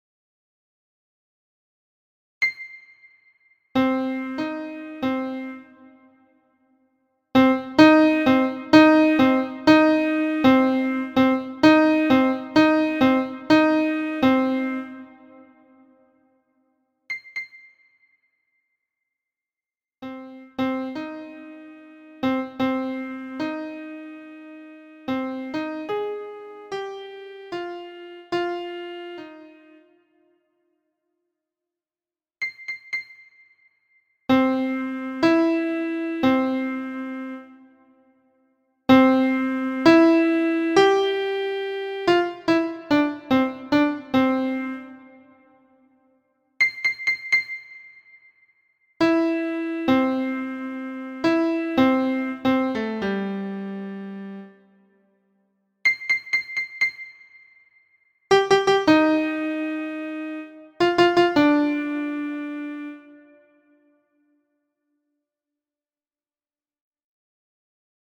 This section contains some MIDI files that demonstrate some interval in the context of some songs.
Thirds